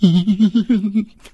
PigFearfulGrin 05.wav